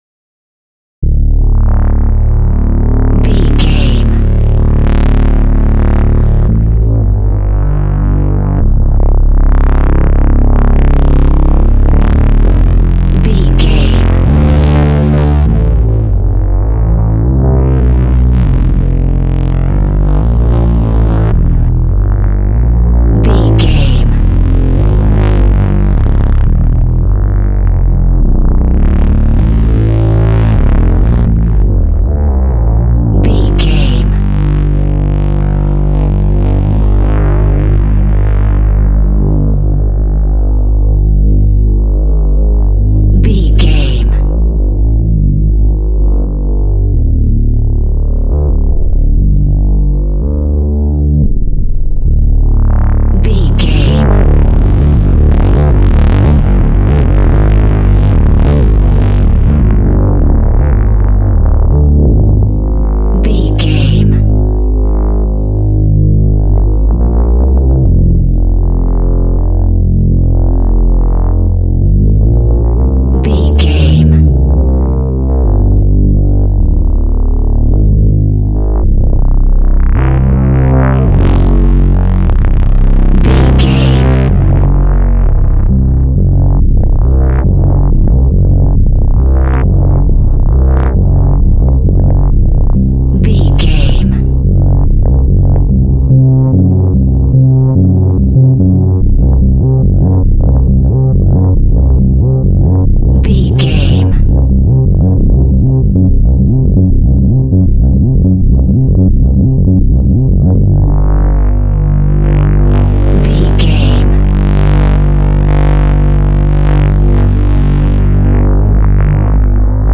Monster Music Sounds.
Atonal
WHAT’S THE TEMPO OF THE CLIP?
Slow
tension
ominous
eerie
Horror synth
Horror Ambience
synthesizer